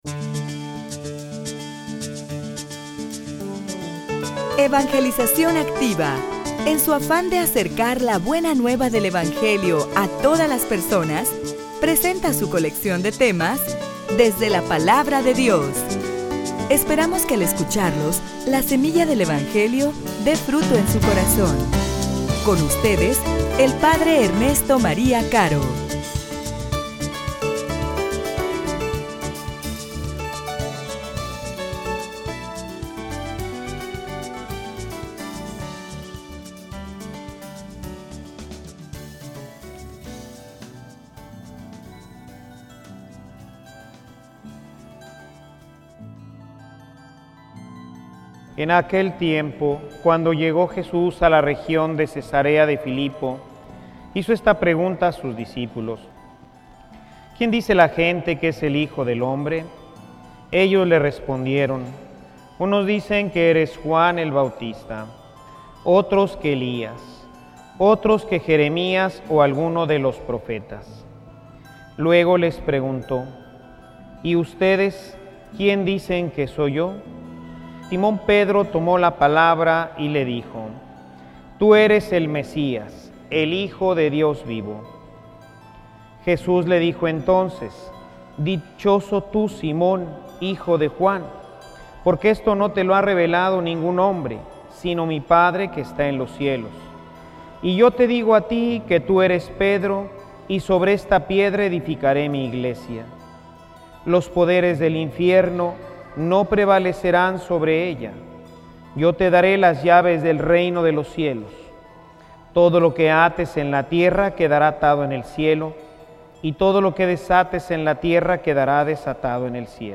homilia_Testigos_del_Resucitado.mp3